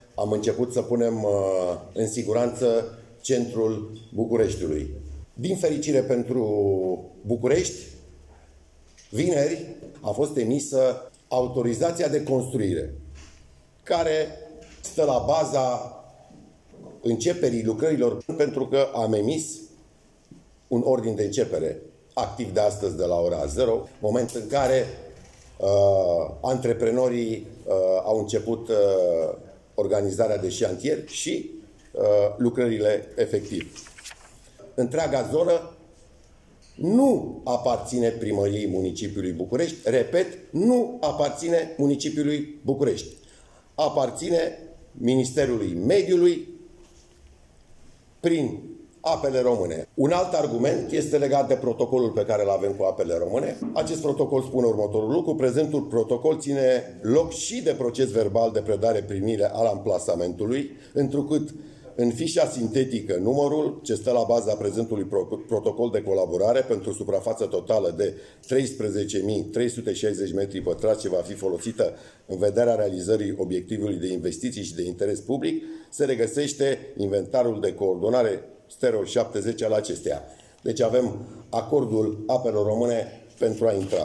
“Primăria Capitalei a emis un certificat de urbanism fals, în care Municipalitatea este menționată ca  proprietar al planșeului”, a explicat Daniel Băluță.